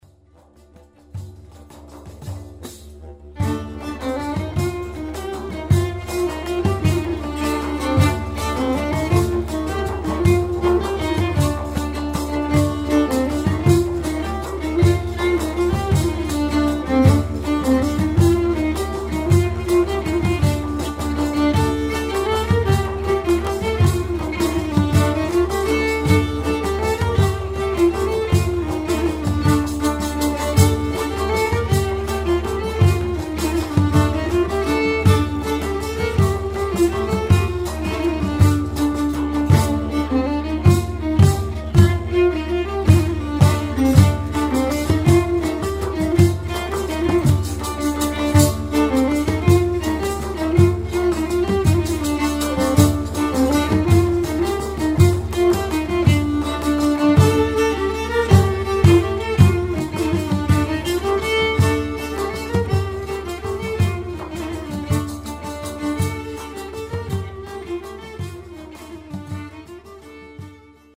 6:18 Kniefiedel, Violine, Digderidoo, Tambourin, Cajon